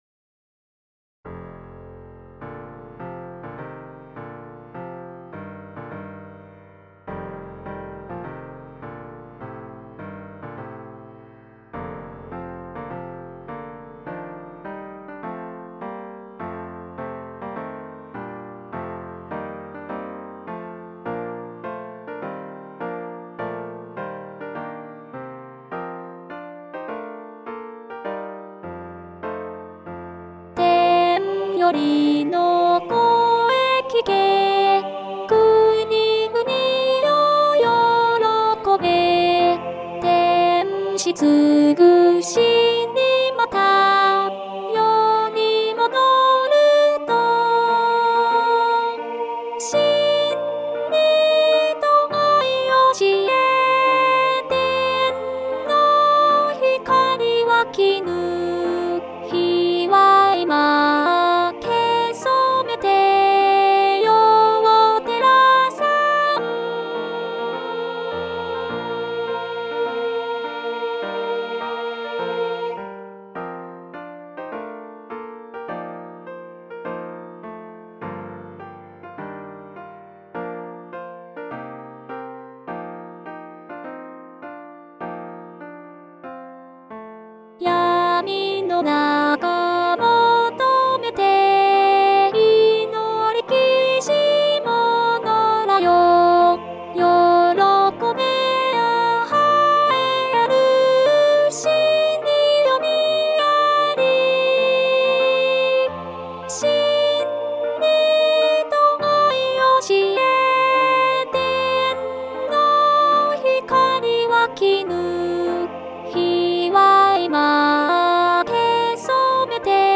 ソプラノ（フレットレスバス音）